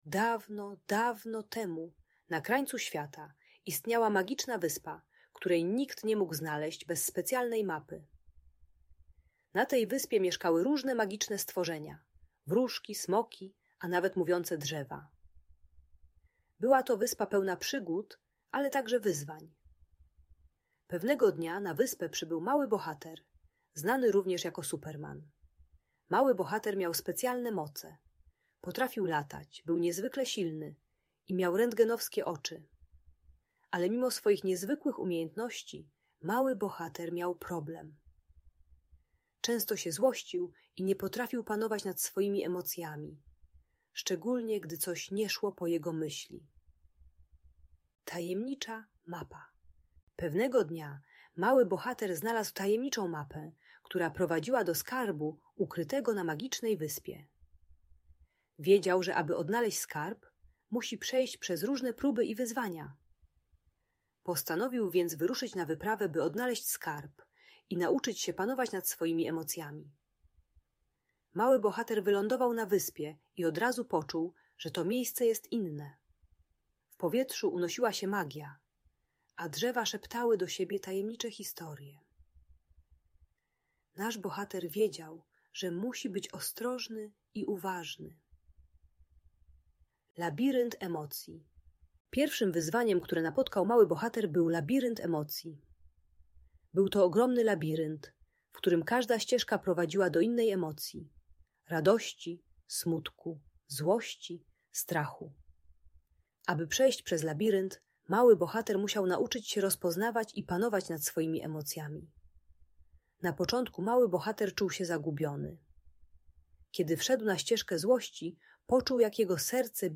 Magiczna Wyspa - Niepokojące zachowania | Audiobajka